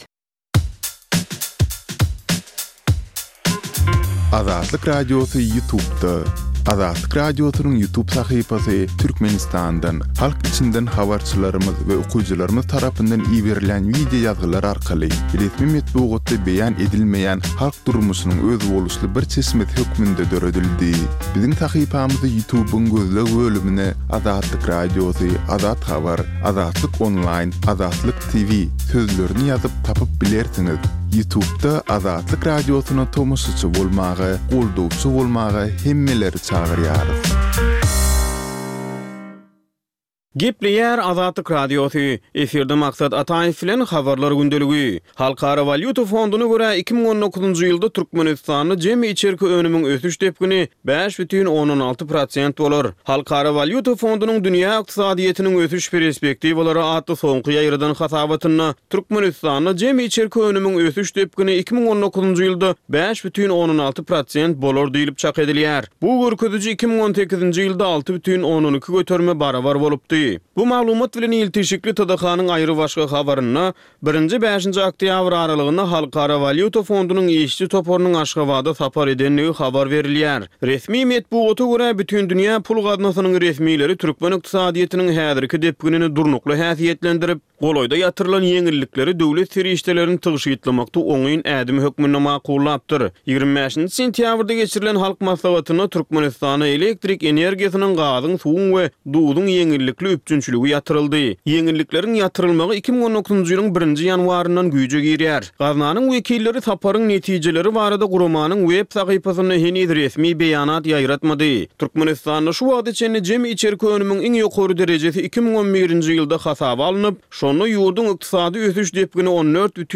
Türkmenistandaky we halkara arenasyndaky soňky möhüm wakalar we meseleler barada ýörite informasion-habarlar programma. Bu programmada soňky möhüm wakalar we meseleler barada giňişleýin maglumatlar berilýär.